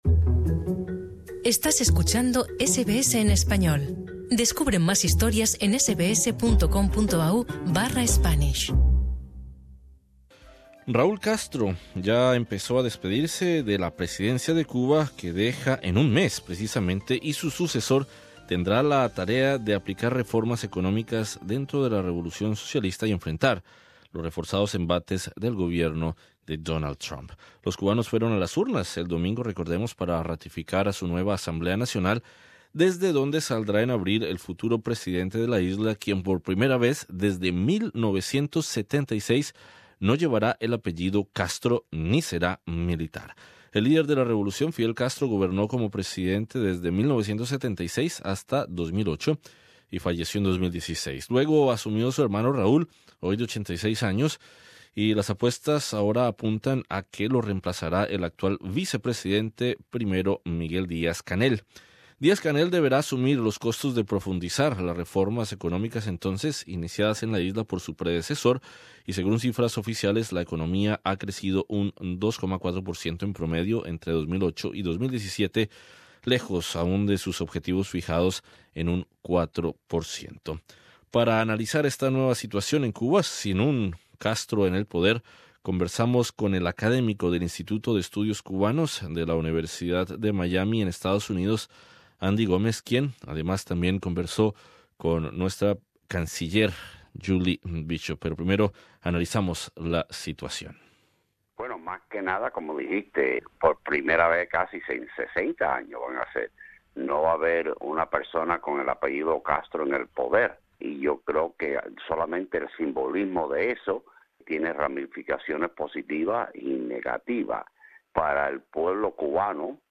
Sobre el cambio en la presidencia cubana, conversamos con el académico del Instituto de Estudios Cubanos ande la Universidad de Miami